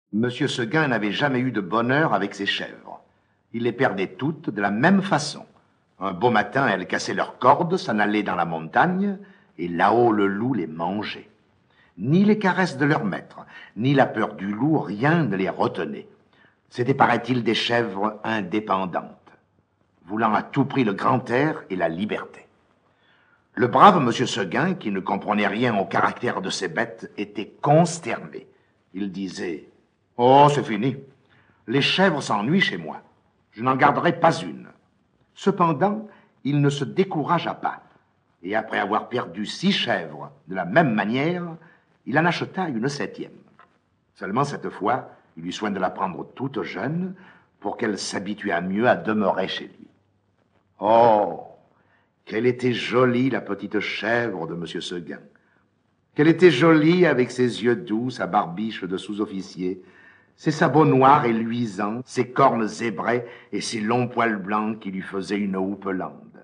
Diffusion distribution ebook et livre audio - Catalogue livres numériques
Enregistrement original de 1953